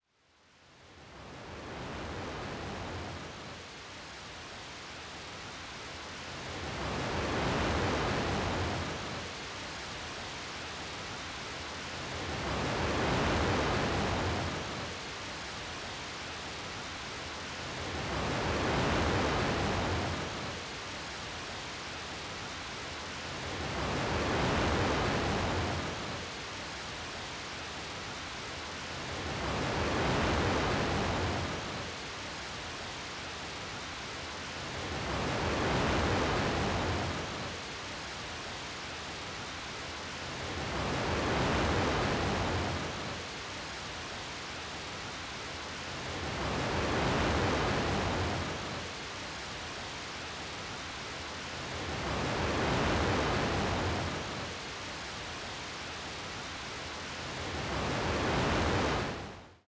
Type BGM
Speed 70%